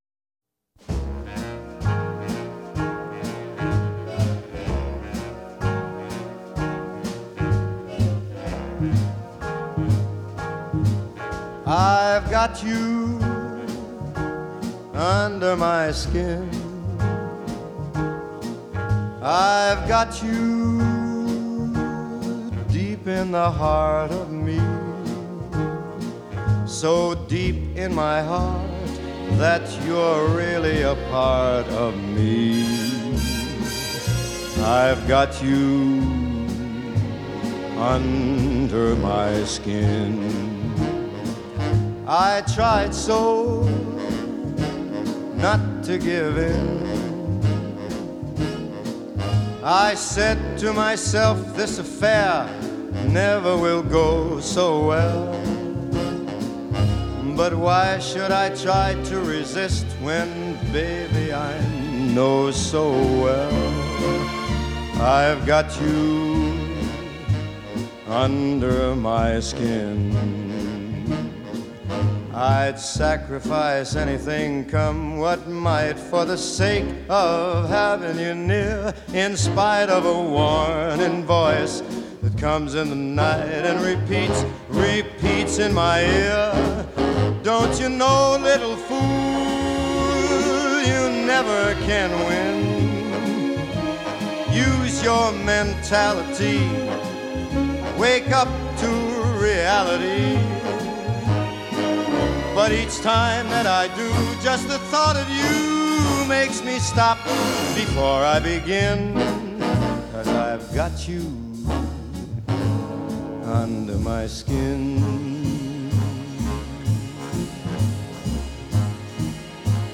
seductive strings building to a brassy climax.